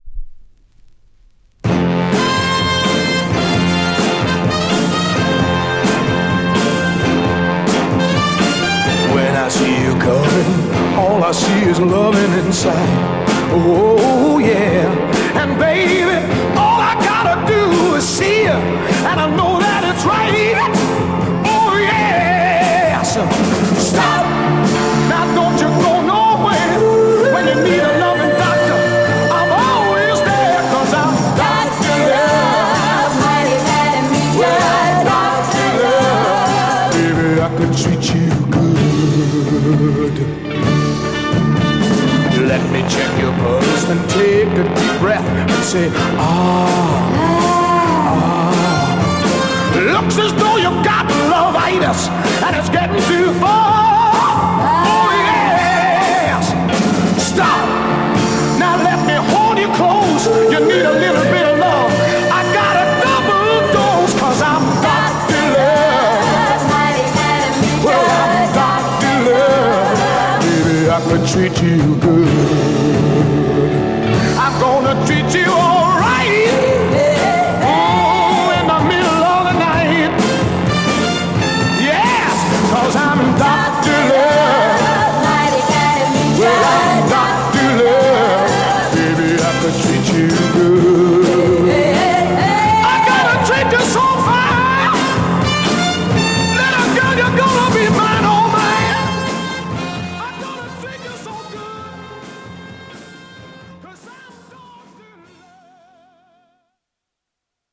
Soul Mod